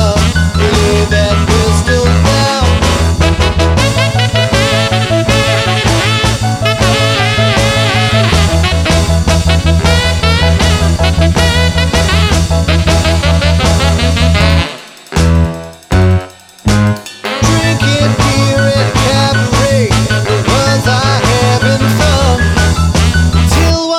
no Backing Vocals Rock 'n' Roll 2:08 Buy £1.50